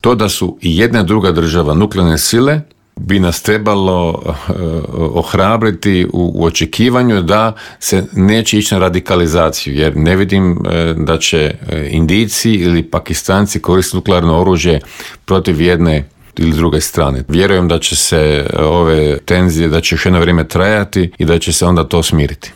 ZAGREB - Aktualne teme s naglaskom na početak konklave, izbor novog pape, ratne sukobe i situaciju u susjedstvu, prokomentirali smo u Intervjuu Media servisa s diplomatom i bivšim ministarom vanjskih poslova Mirom Kovačem.